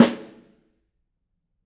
Snare2-HitSN_v9_rr1_Sum.wav